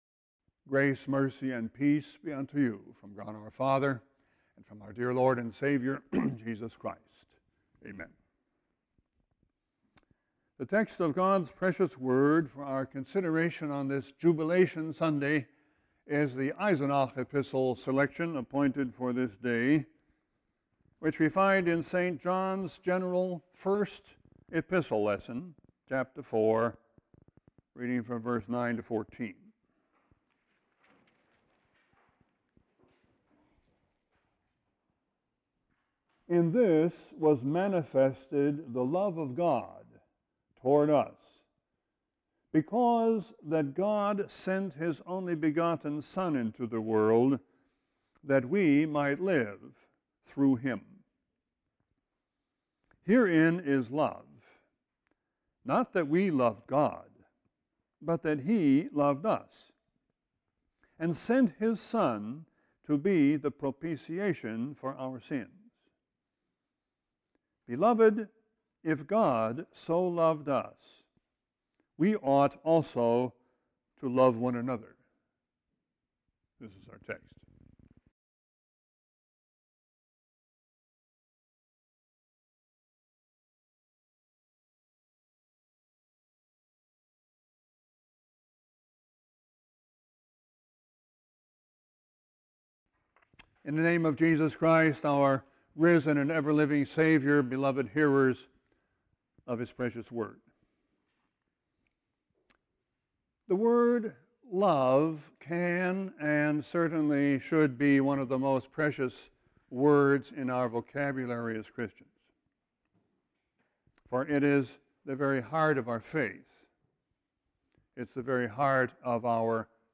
Sermon 4-17-16.mp3